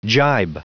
Prononciation du mot gibe en anglais (fichier audio)
Prononciation du mot : gibe